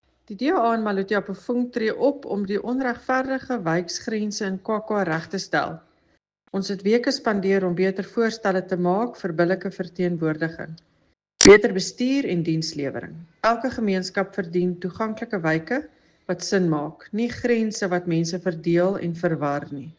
Afrikaans soundbite by Cllr Eleanor Quinta and